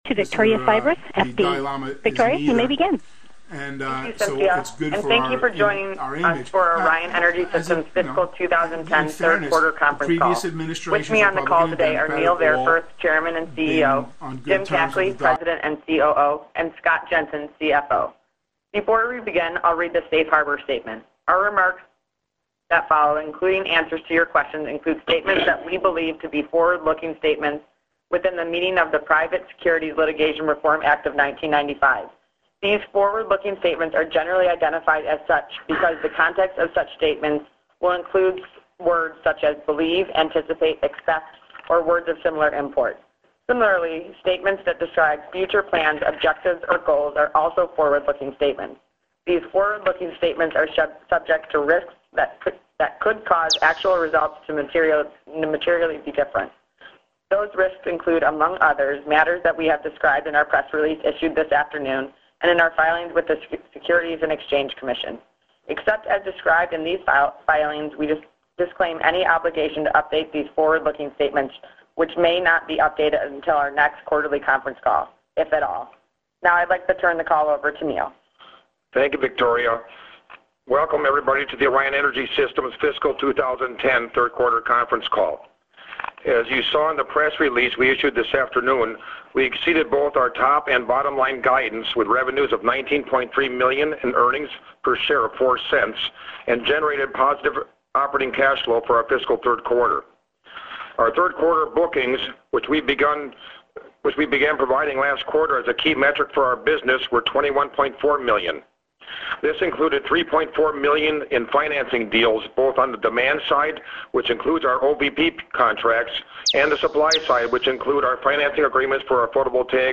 Subs: Earnings Call & Notes $$